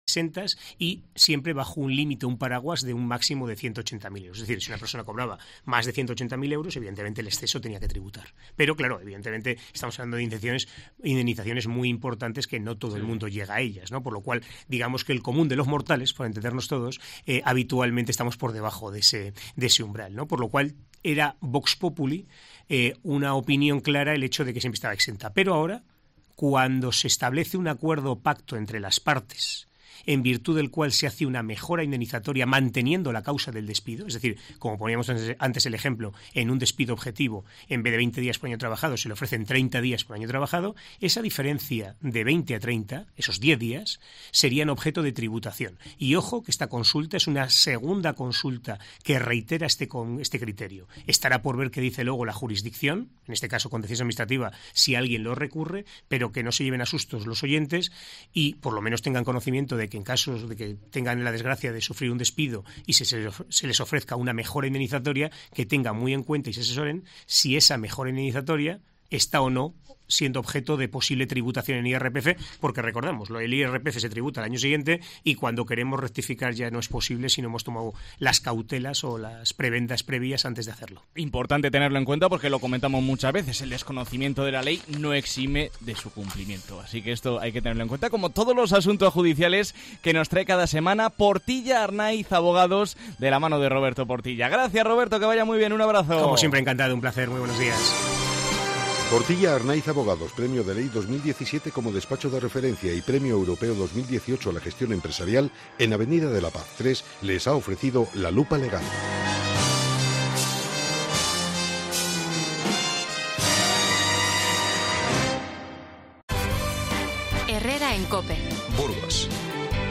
Abogado burgalés